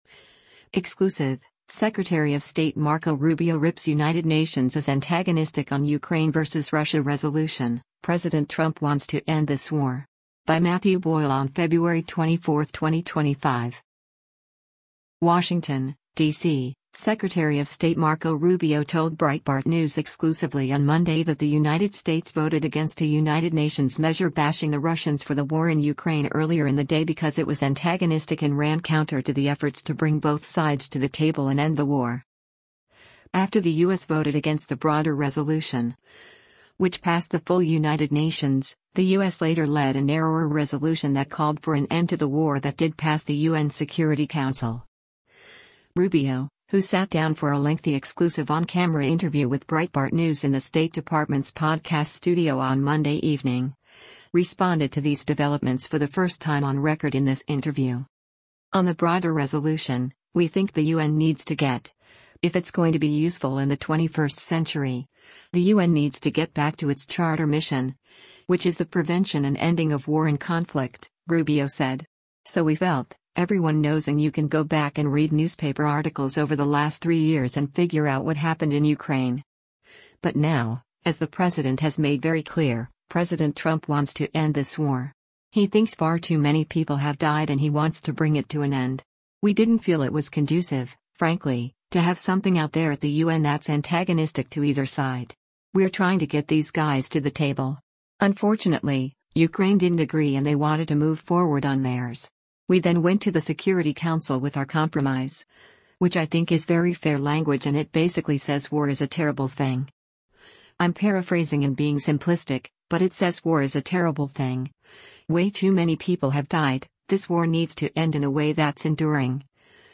Rubio, who sat down for a lengthy exclusive on-camera interview with Breitbart News in the State Department’s podcast studio on Monday evening, responded to these developments for the first time on record in this interview.